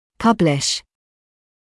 [‘pʌblɪʃ][‘паблиш]публиковать, издавать